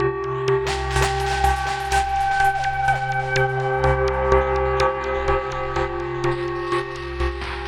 tx_synth_125_ambient_GCFC.wav